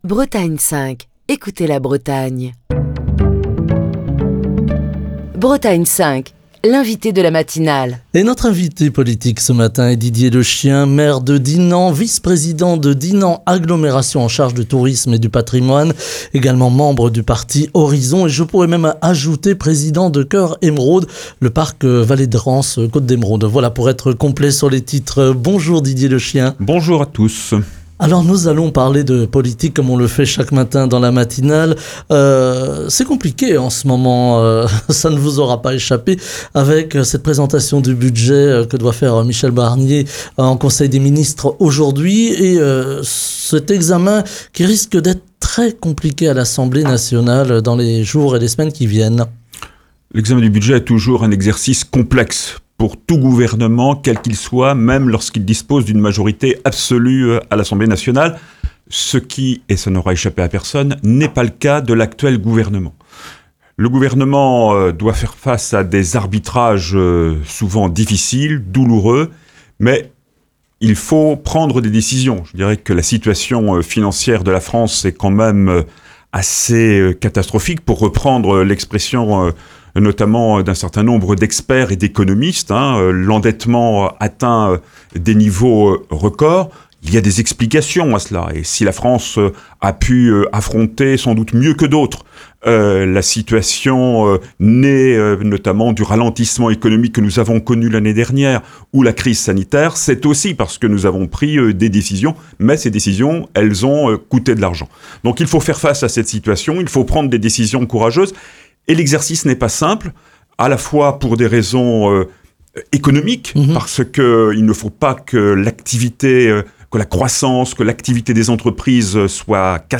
Ce jeudi, Didier Lechien, maire de Dinan, vice-président de Dinan Agglomération en charge du tourisme et du patrimoine, président de Cœur Émeraude, membre du parti Horizons est l'invité de Bretagne 5 pour commenter l'actualité politique et les conséquences de ces économies budgétaires sur les projets et le fonctionnement des collectivités territoriales.